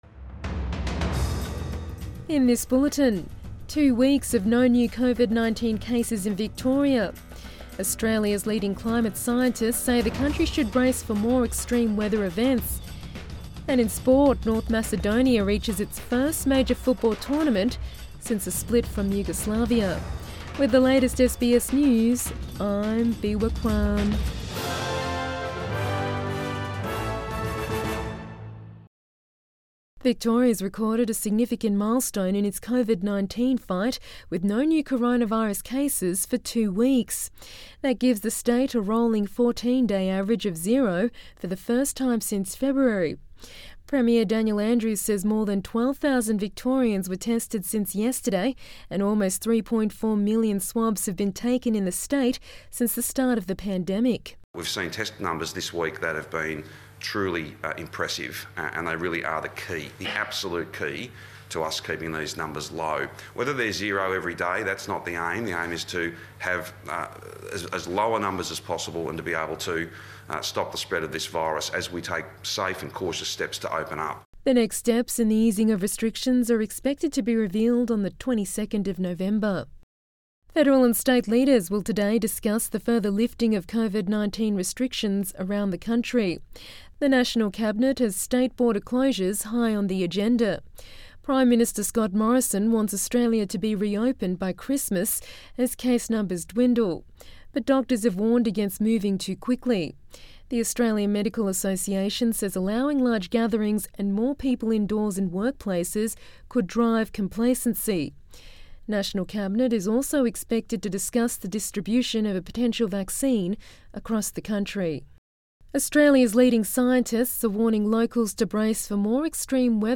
Midday bulletin 13 November 2020